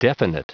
Prononciation du mot definite en anglais (fichier audio)
Prononciation du mot : definite
definite.wav